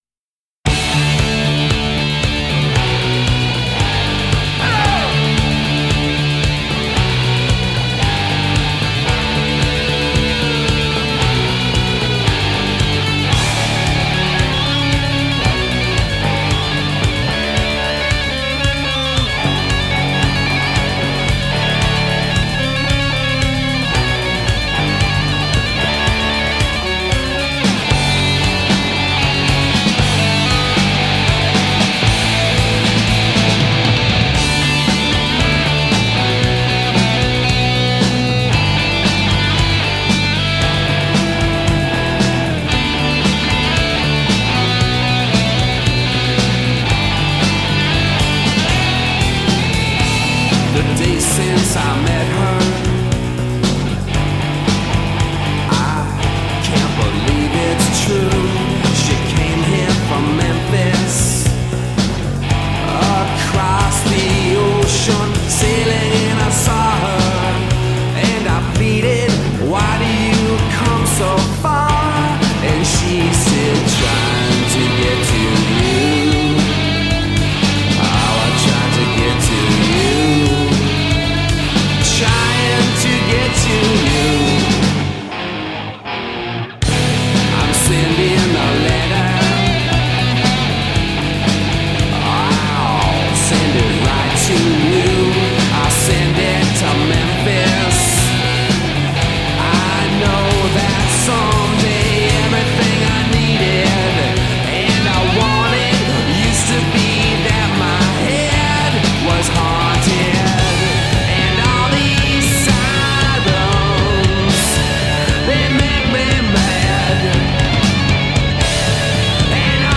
A soupçon of sonic and lyrical wonder.
alternative rock